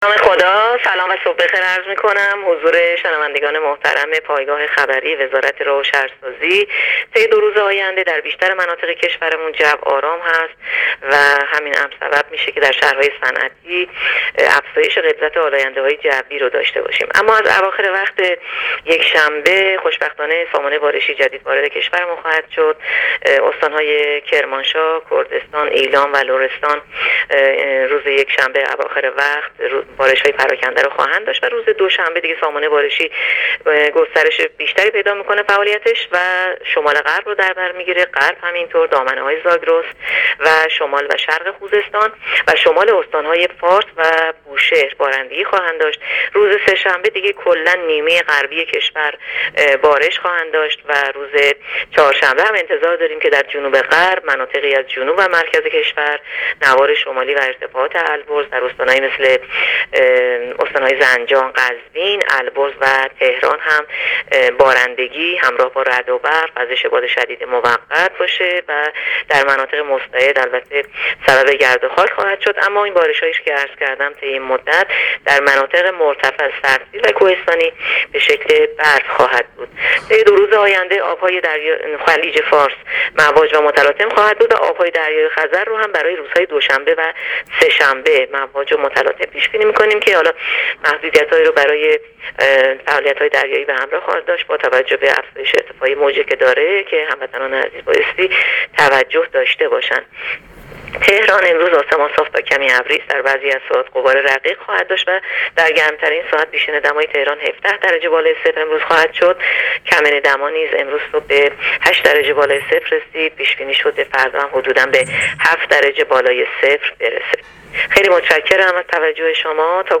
گزارش رادیو اینترنتی پایگاه‌ خبری از آخرین وضعیت آب‌وهوای سوم آذر؛